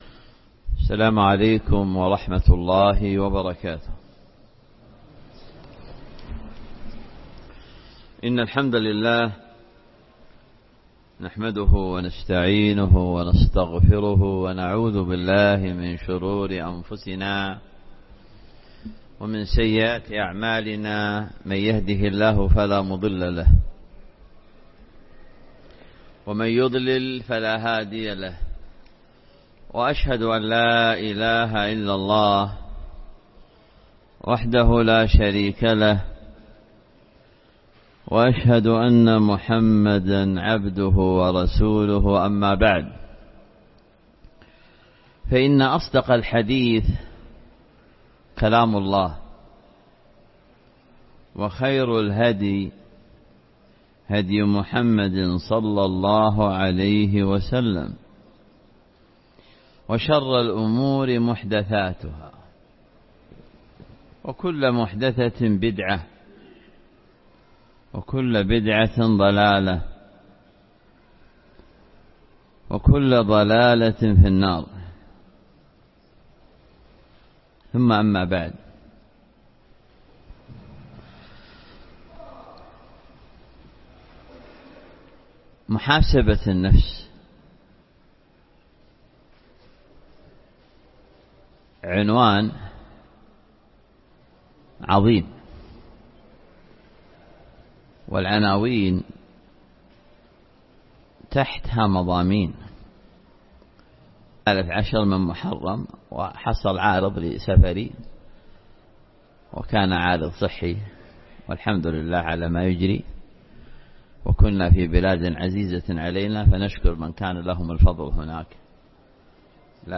الدرس الأسبوعي في شرح كتاب فضائل القرآن وكتاب بلوغ المرام